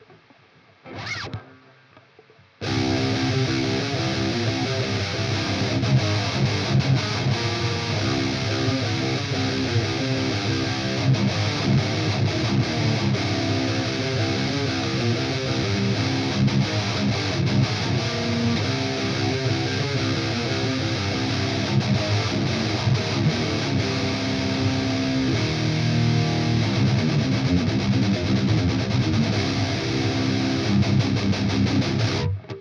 here's a little shootout between the OD808, OD820 and an 808 modded TS9
it really doesn't matter all that much, I can hardly hear a difference
The TS9-808 clip and the maxon 808 clip sounds the same to me, the 820 have a little more lowend rumble..
to me the 808 sounds a little bit smoother and thicker but less open than the 9-808.